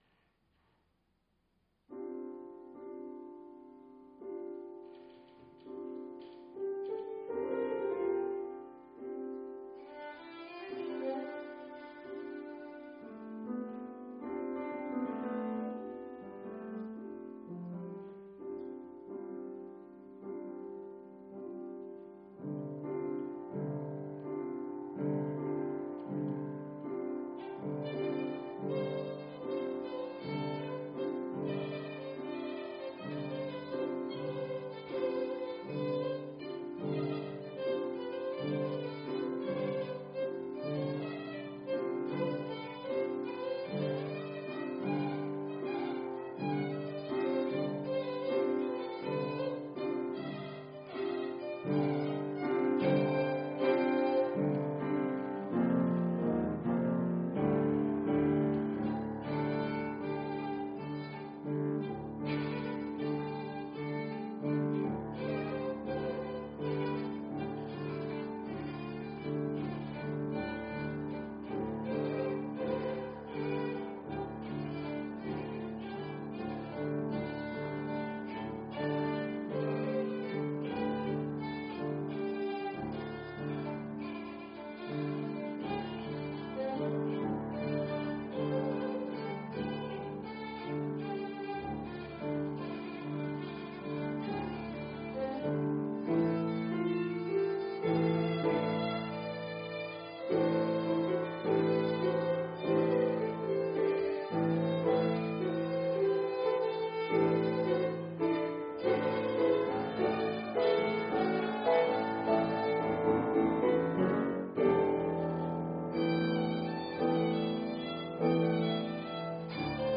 Voor viool en piano
Viool / violin
Piano